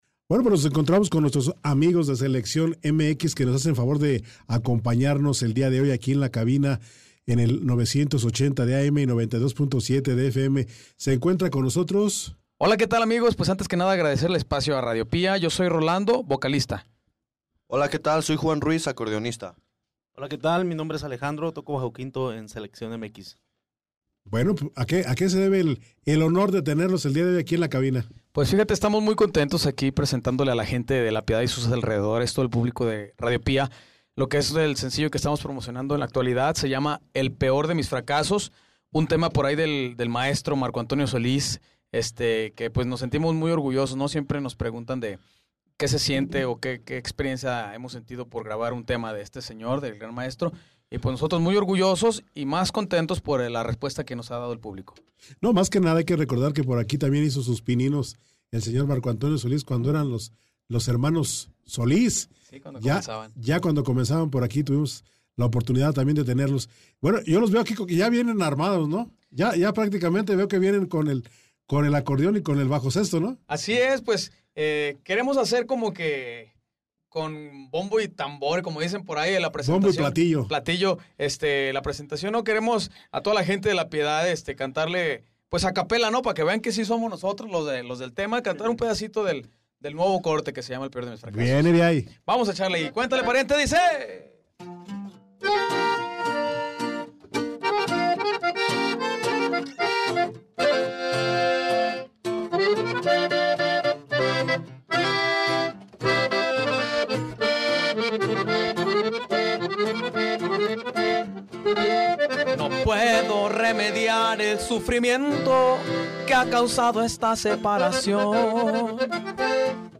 SELECCIÓN MX PRESENTA «EL PEOR DE MIS FRACASOS» (entrevista) – RADIO PÍA